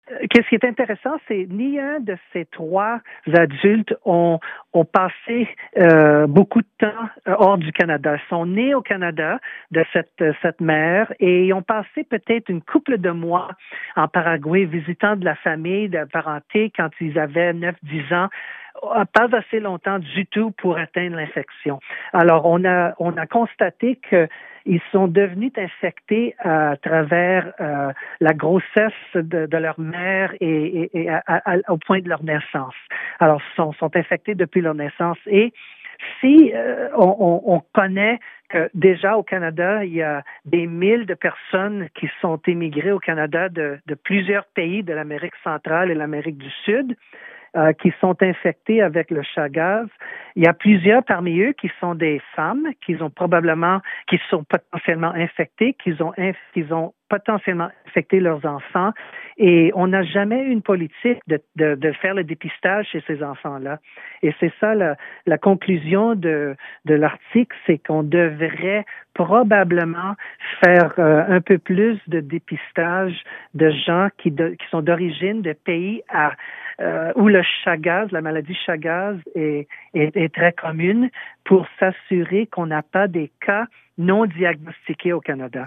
Lors de l’entrevue, il a expliqué les principales découvertes faites de façon plutôt hasardeuse par lui et par son équipe lors de consultations en clinique.